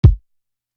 Electric Chair Kick.wav